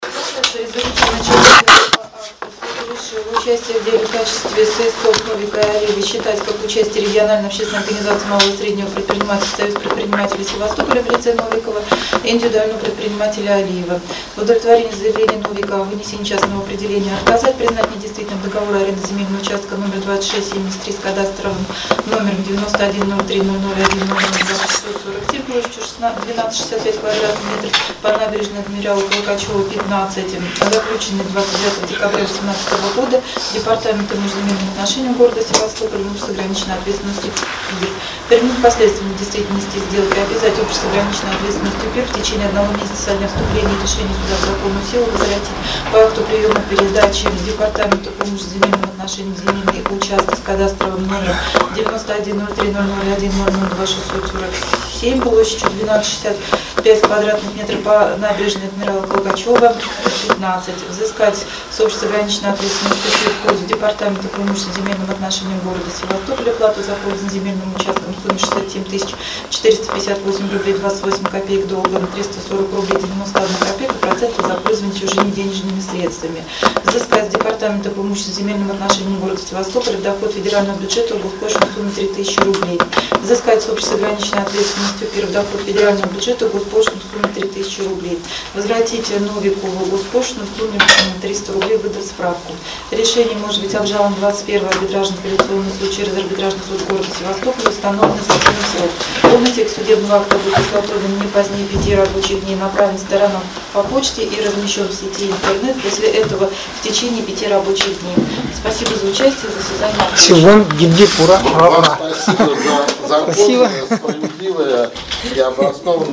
Приводим запись  резолютивной части судебного решения от 17.12.19 г.